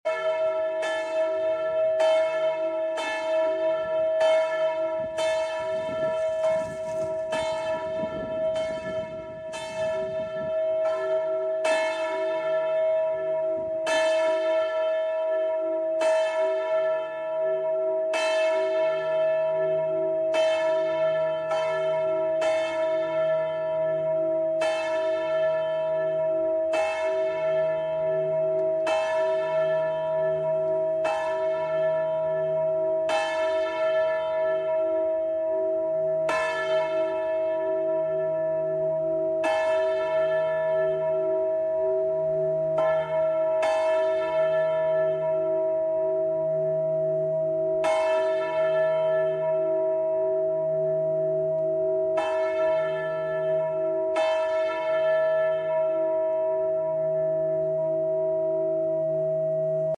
large bronze bells along with sound effects free download
large bronze bells along with corrisponding 639 Hz frequency.